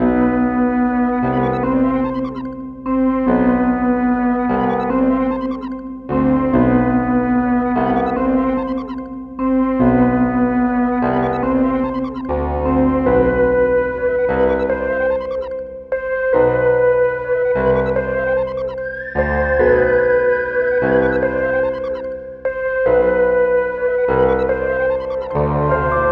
SOUTHSIDE_melody_loop_warm_up_147_Cm-Bm.wav